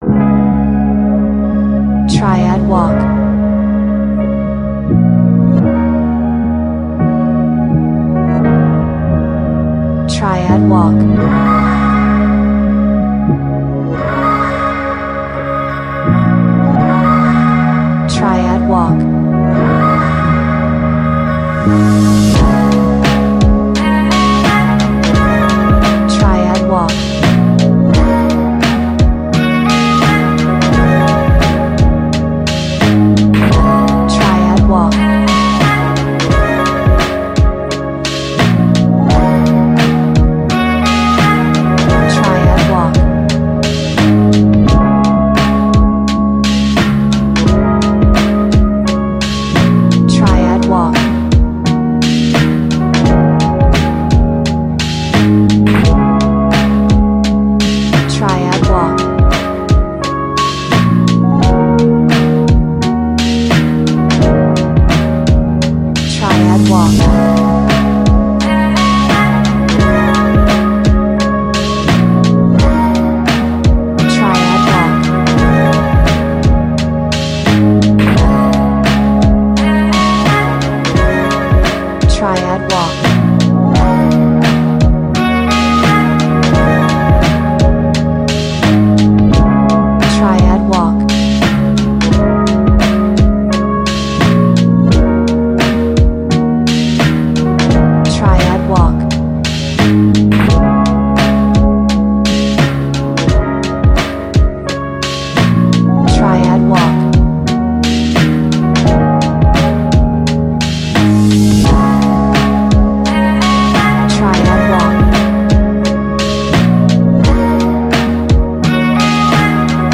クールなR&Bサウンド
Cool R&B sound.